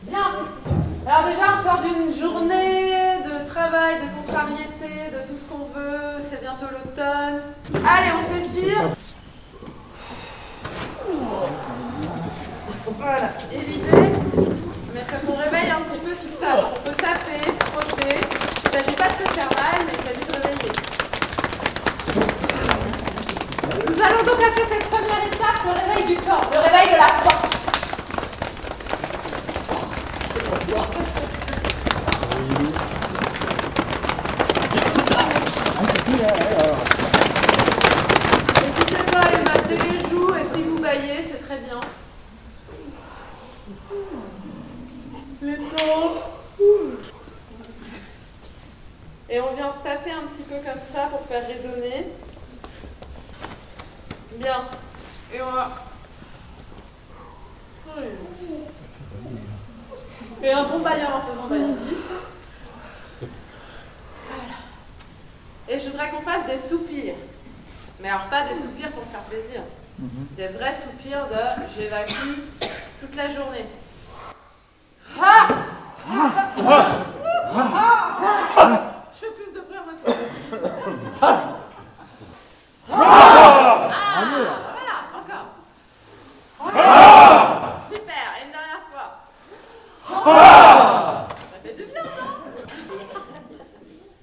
Echauffement vocal
Ces enregistrements au format MP3 ont été réalisés lors de la répétiton du 22 septembre 2025.